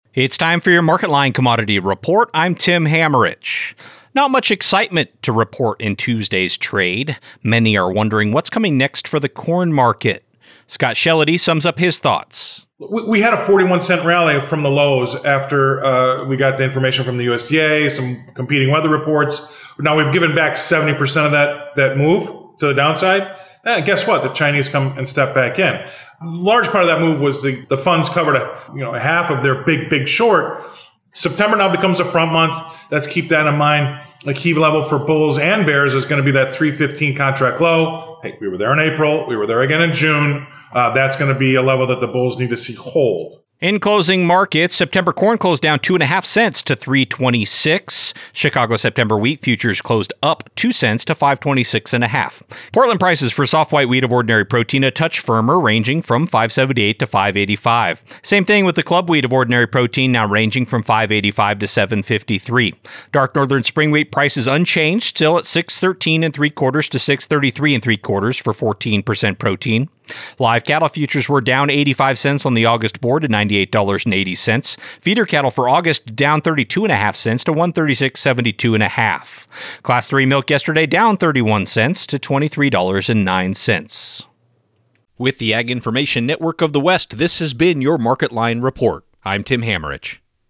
News Reporter